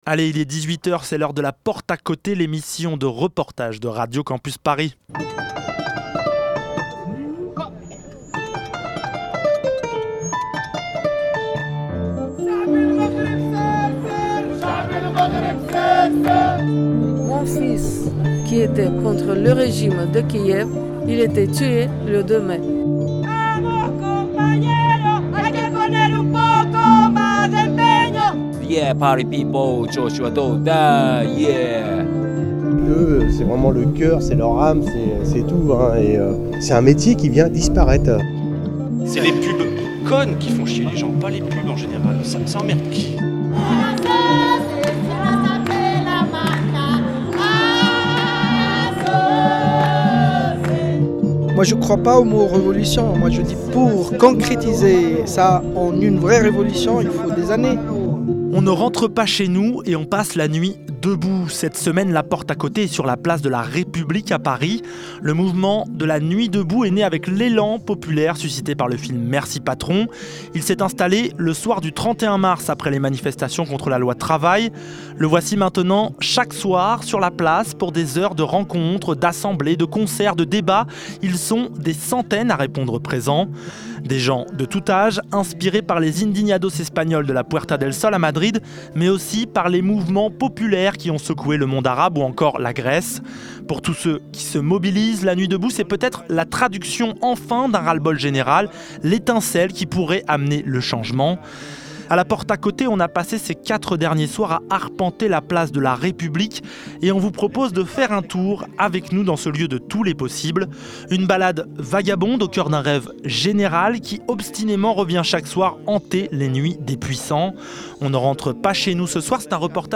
La Porte à Côté , c'est l'émission de reportage de Radio Campus Paris.